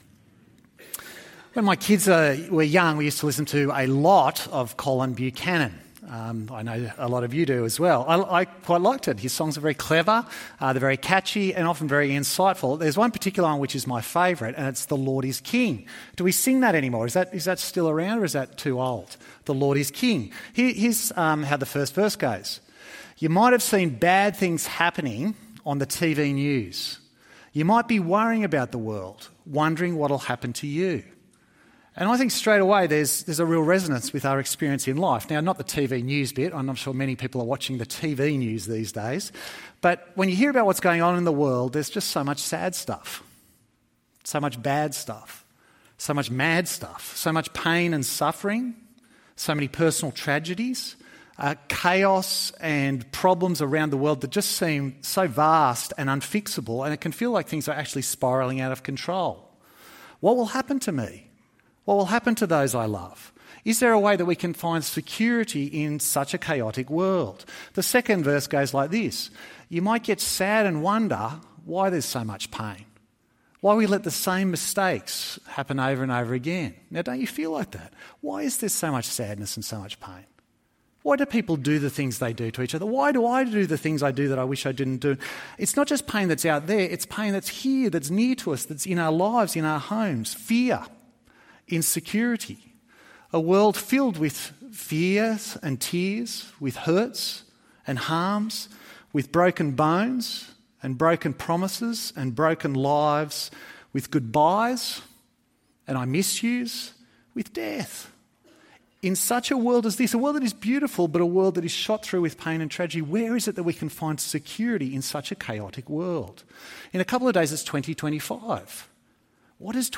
EV Church Sermons